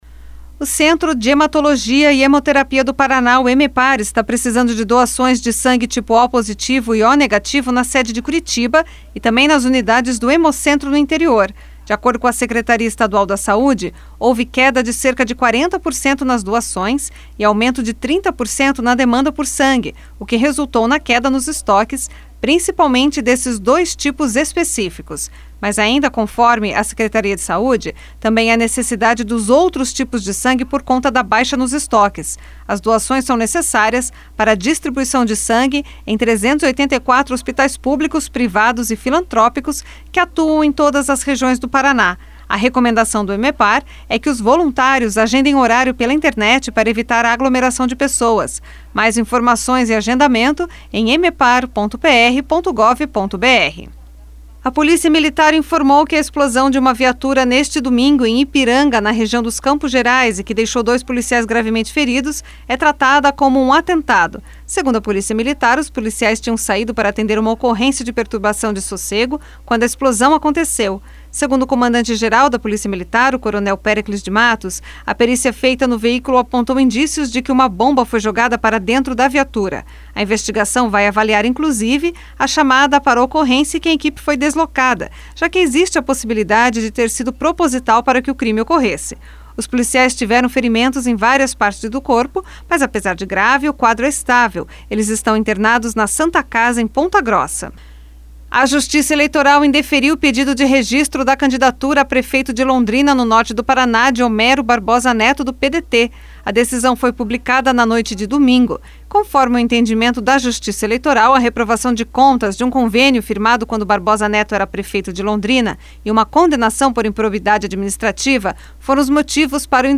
Giro de Notícias Tarde SEM TRILHA